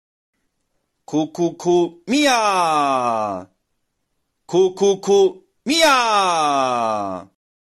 powerful third eye,pineal gland activation frequency.